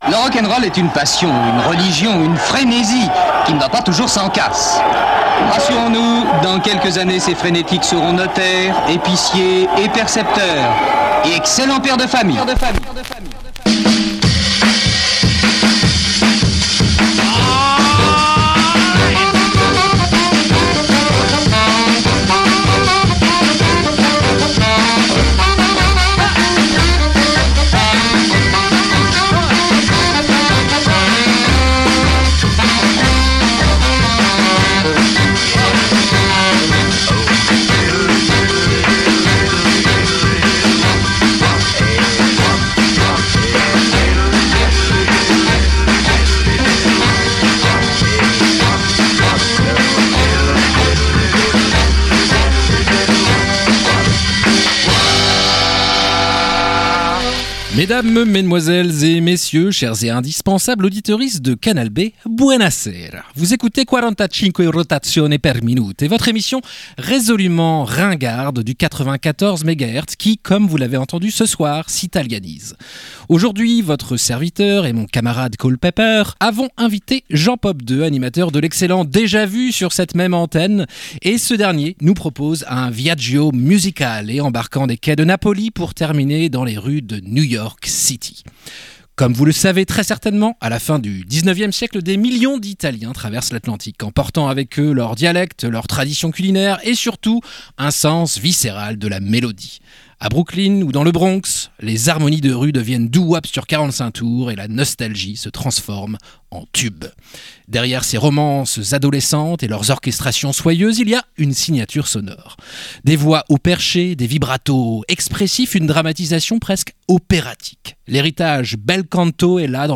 Rock’n’roll, Mambo, RnB, Garage, Doo Wop & Cha Cha Cha